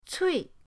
cui4.mp3